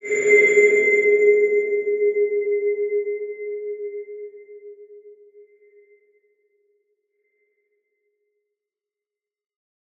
X_BasicBells-G#2-ff.wav